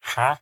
Minecraft Version Minecraft Version 1.21.4 Latest Release | Latest Snapshot 1.21.4 / assets / minecraft / sounds / mob / villager / haggle2.ogg Compare With Compare With Latest Release | Latest Snapshot
haggle2.ogg